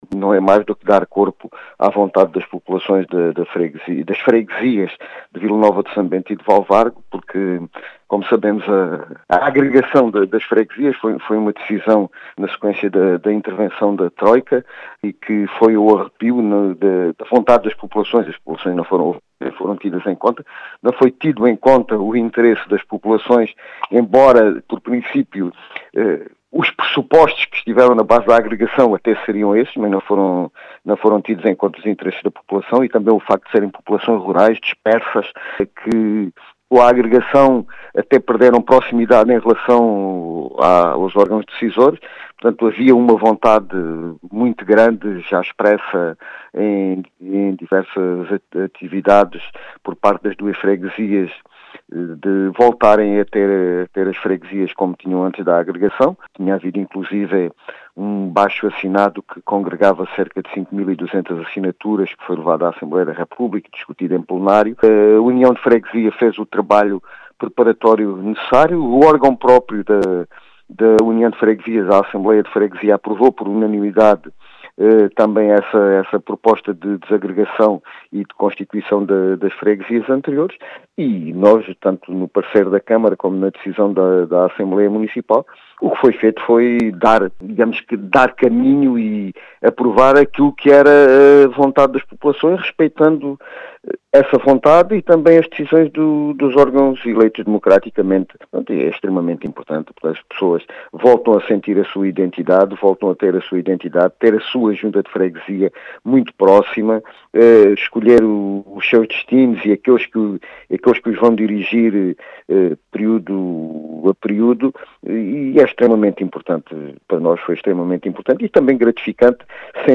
As explicações foram deixadas por João Efigénio Palma, presidente da Câmara Municipal de Serpa, que diz ter sido a “vontade das populações”.